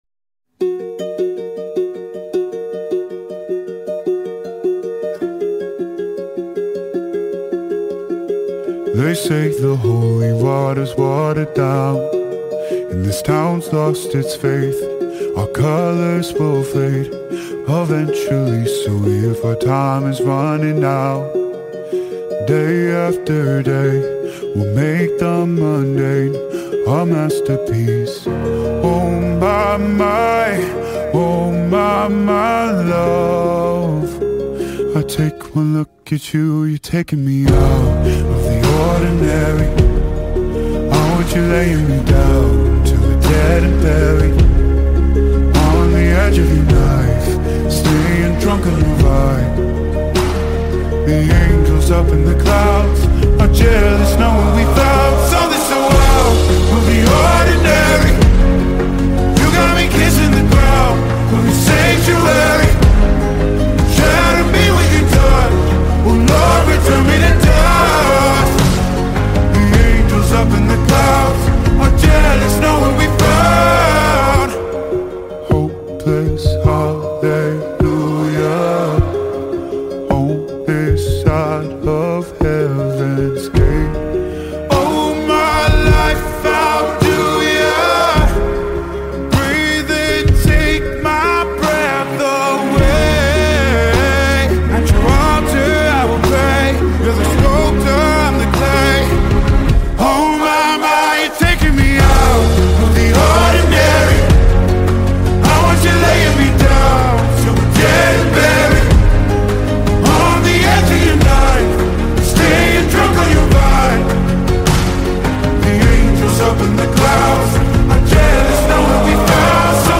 فوق شنیدنی و رمانتیک
در نسخه Slowed و کند شده مناسب ادیت با کیفیت عالی
عاشقانه